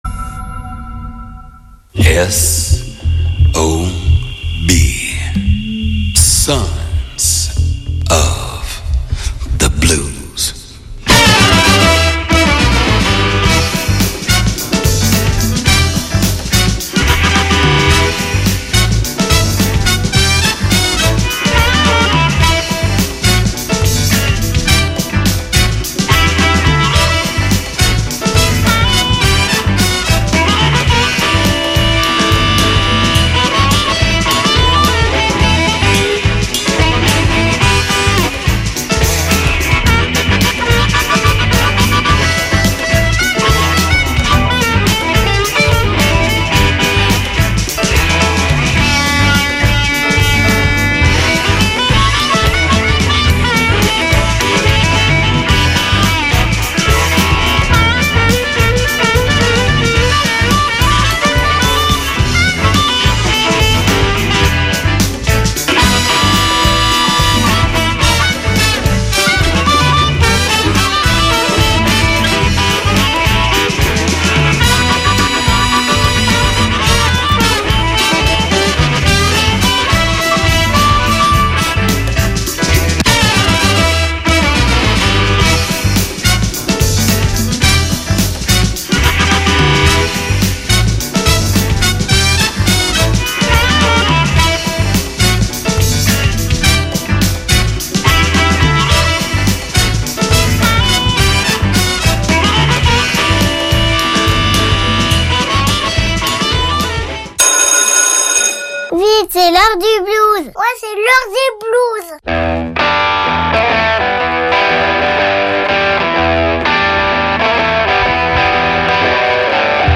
Puisque nous sommes tous des fils et filles du blues, il est bon de se retrouver chaque jeudi à 21H pour 1H de blues d’hier, d’aujourd’hui ou de demain.I